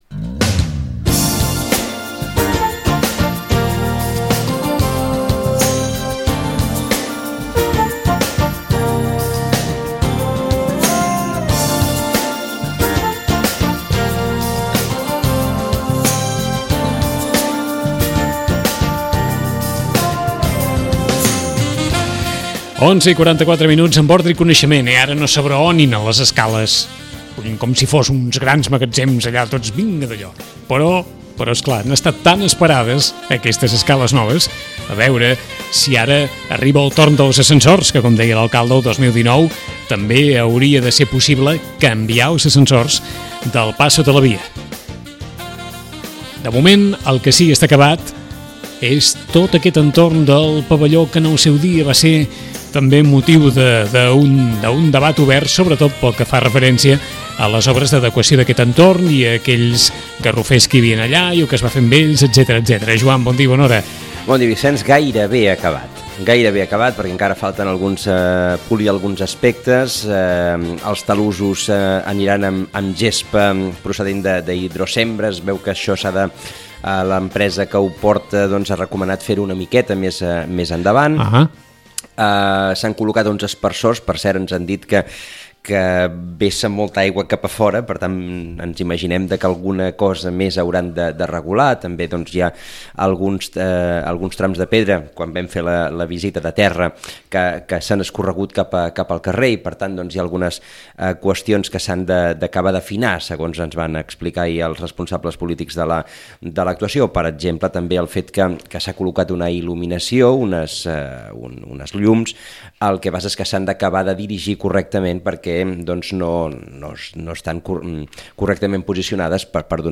L’alcalde de Sitges, Miquel Forns i el regidor d’esports Eduard Terrado han presentat la reforma de l’aparcament annex als pavellons de Pins Vens. Tots dos han insistit en la legalitat de la tramitació urbanística que ha permés fer l’actuació, avalada per la Comissió d’Urbanisme de Barcelona, i en la substitució dels garrofers que no han pogut ser transplantats. El pressupost, de 212.000€, està inclós en les inversions sostenibles.